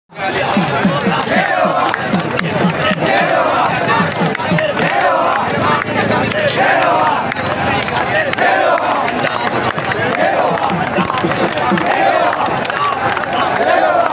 ڪئنال منصوبو واپس ٿيڻ کان ھڪ ڏينھن اڳ سامراج خلاف لڳل ڌرڻي ۾ سخت نعريبازي ڪندي.